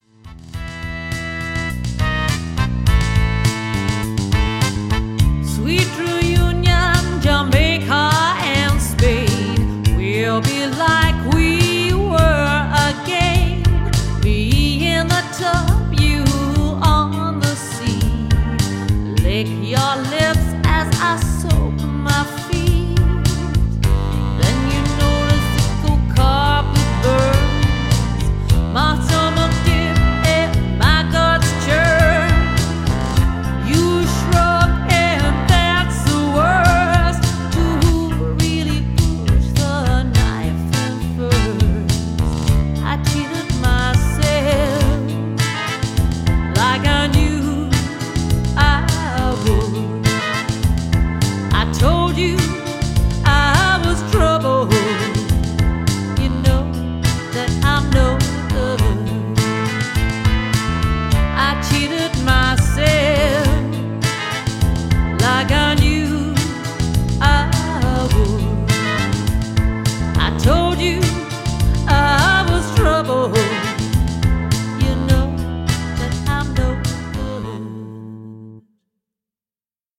a highly experienced and professional two-piece band